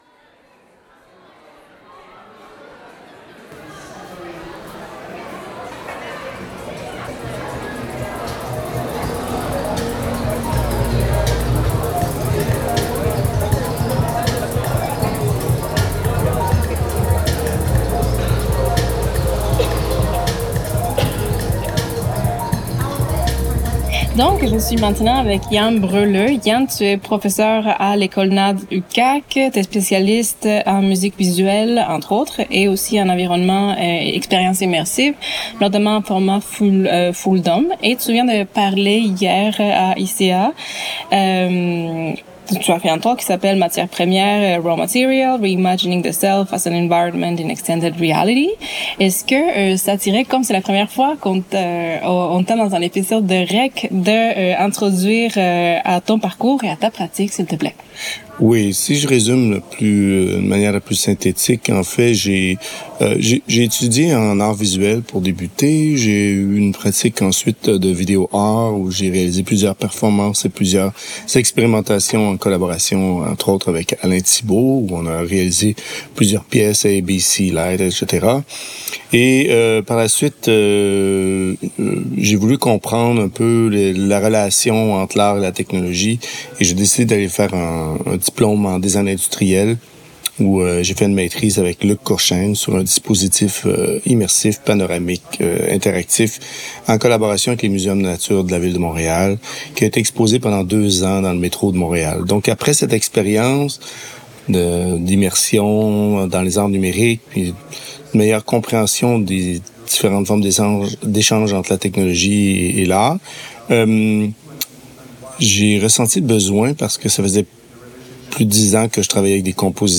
Entretien
This is the final episode in a three-part series, recorded at ISEA 2025 in Seoul.
Dernier d’une série de trois, cet épisode a été enregistré lors d’ISEA 2025 à Séoul.